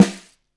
TRASHIER.wav